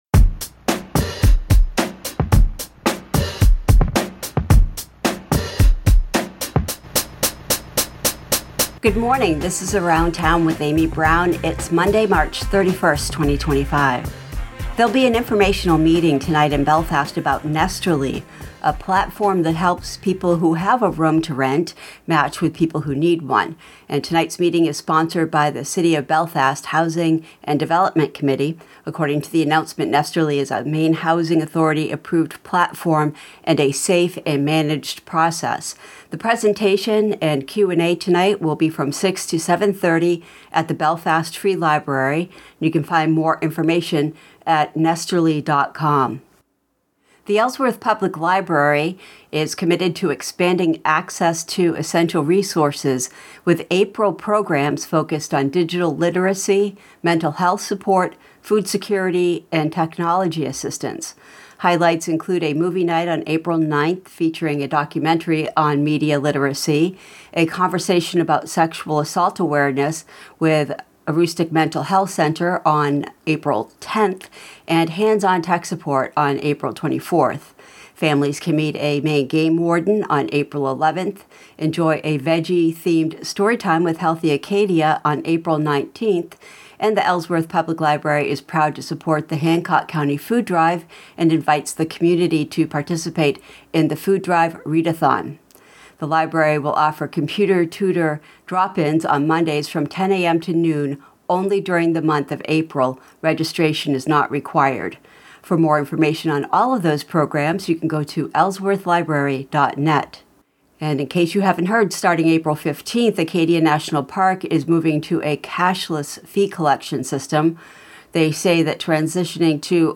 Around Town 3/31/25: Local News, Culture and Events